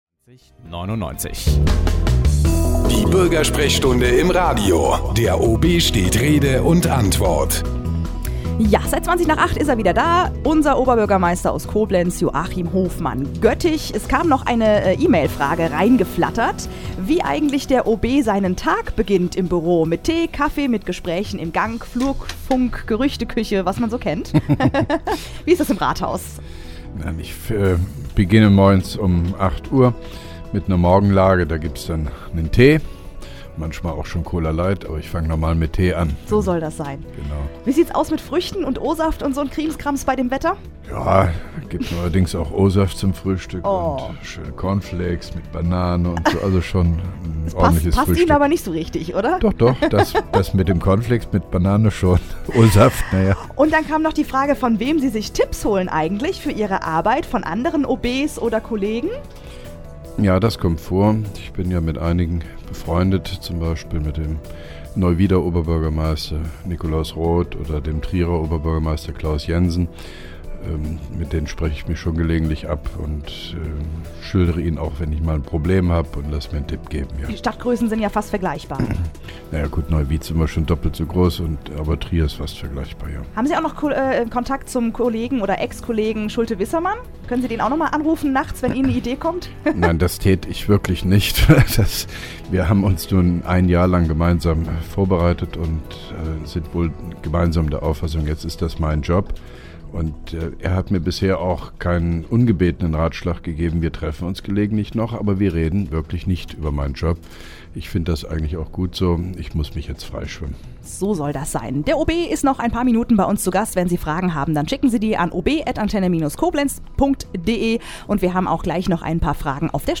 (2) Koblenzer OB Radio-Bürgersprechstunde 28.09.2010
Interviews/Gespräche